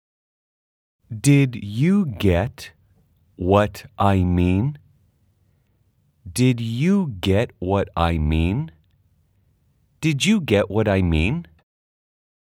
[겁없이 잉글리시 20일 동사편]의 문장은 단어부터 또박또박 연습하고, 조금 빠르게, 아주 빠르게 3가지 속도로 구성되어 있습니다.
/ 디쥬 갯 와라이 / 미인 /
what I는 /왓 아이/로 또박또박 발음하지 말고 /와라이/로 붙여서 발음하세요.